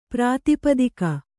♪ prātipadika